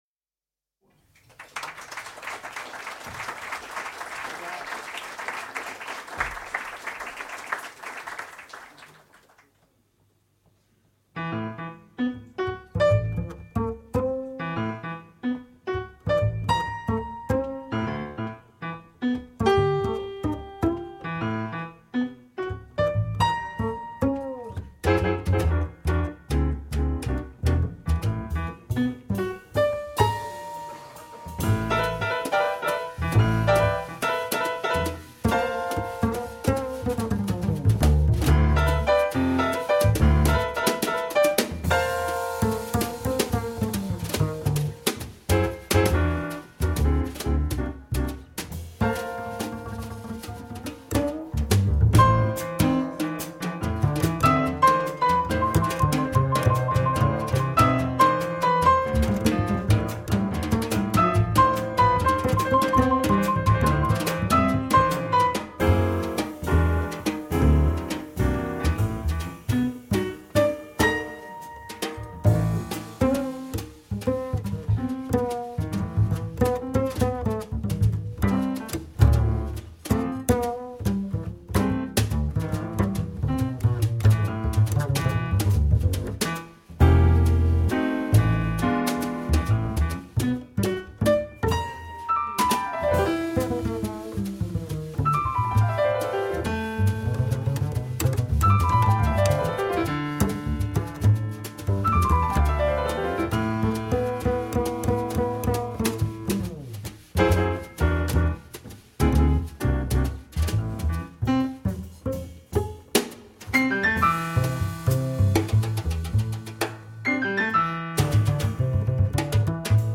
tenor saxophone
piano
double bass